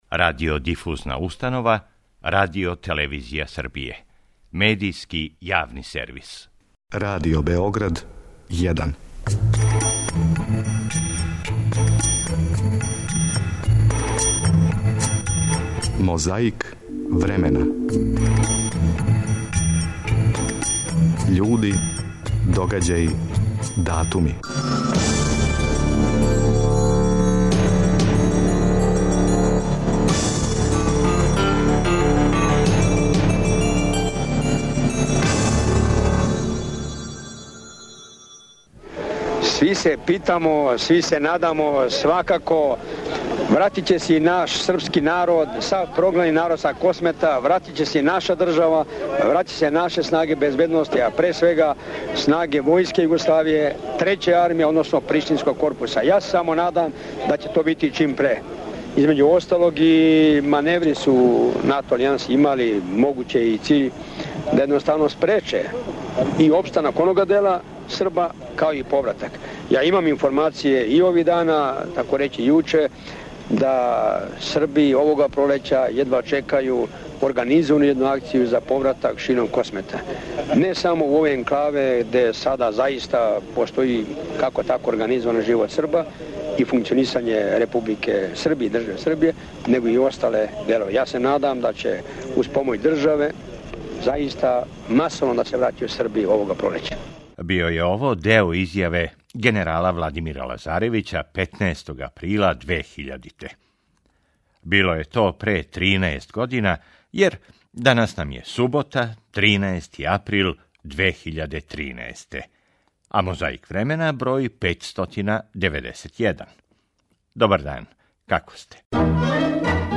Истовремено је проглашена српска патријаршија са првим српским патријархом Јоаникијем. 14. априла 2001. гост у студију Првог програма Радио Београда био је председник Скупштине Србије Драган Маршићанин.
Дан је био сунчан и леп, а заслужни репортер на висини задатка у датим околностима.